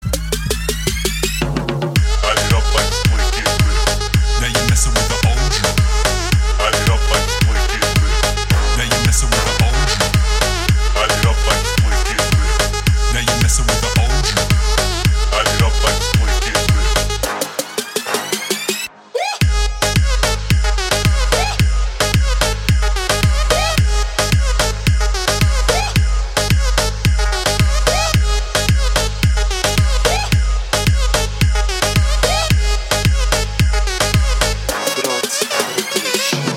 • Качество: 128, Stereo
мужской голос
восточные мотивы
EDM
Club House
басы
качающие
electro house